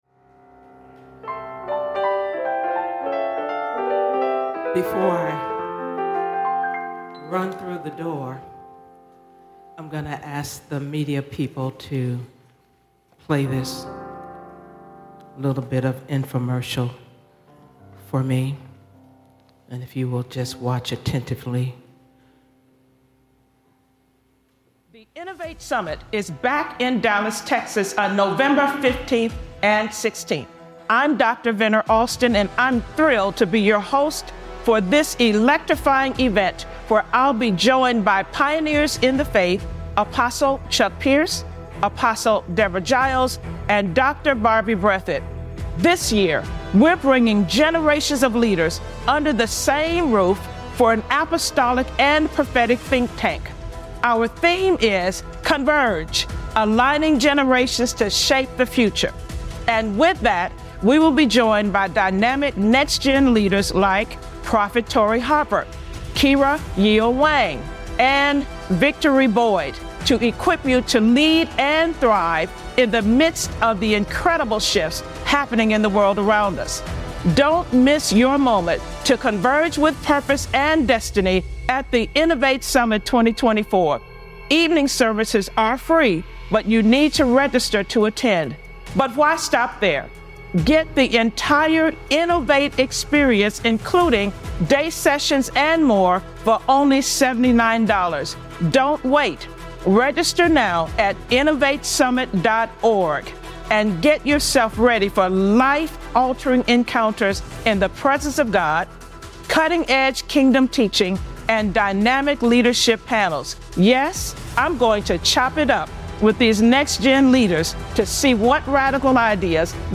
Sunday First Fruits Celebration Service